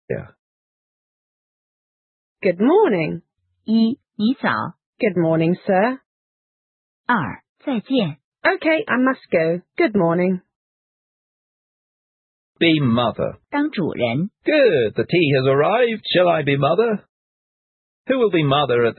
（1）见面时的语调欢快，重读Good ,降读morning